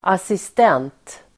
Uttal: [asist'en:t]